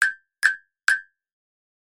Blink sound effect (CC0)
ahh blink cartoon goofy marimba xylophone sound effect free sound royalty free Sound Effects